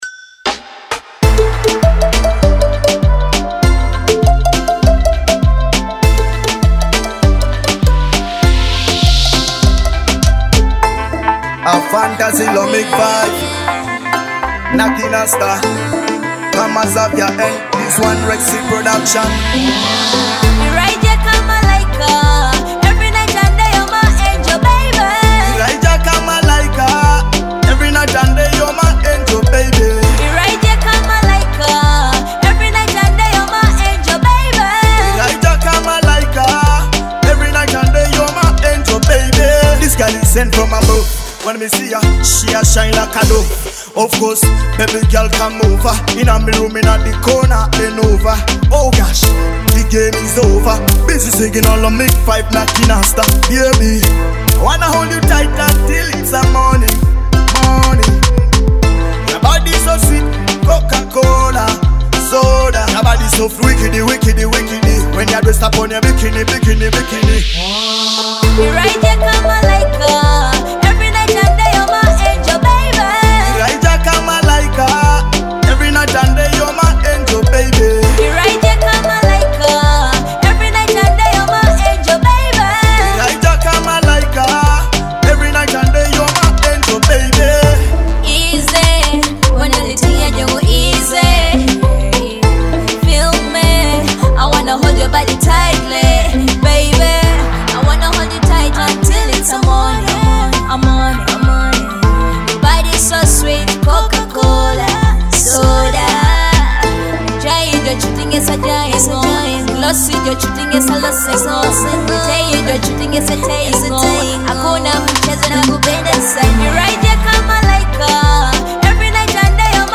a captivating blend of rhythmic beats and powerful vocals.
love Dancehall hit